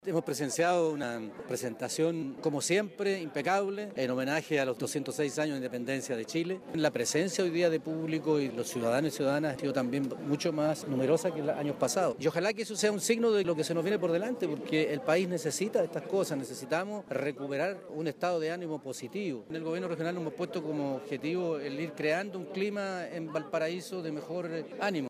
El Intendente Regional, Gabriel Aldoney, se refirió a la alta presencia de público durante el desfile, donde agregó que en el país es necesario tener instancias como estas, ya que según mencionó, es necesario recuperar un estado de ánimo positivo en el país.